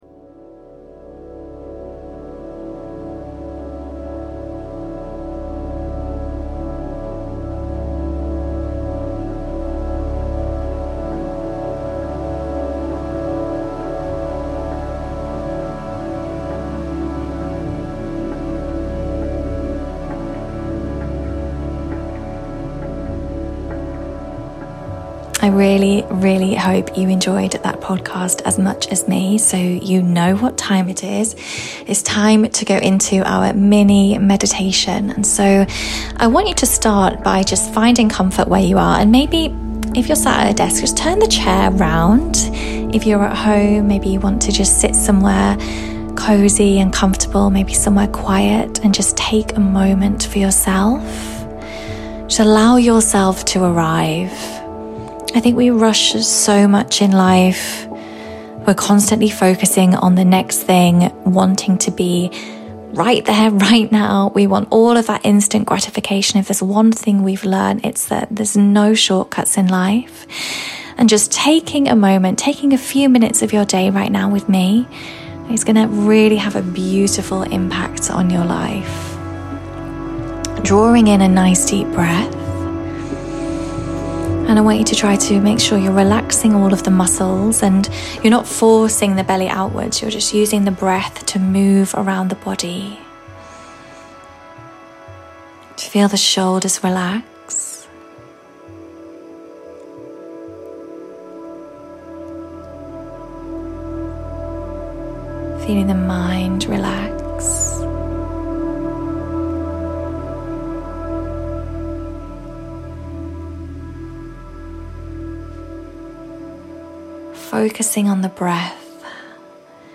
Mini Medi 40 | Deep Relaxation and Body Alignment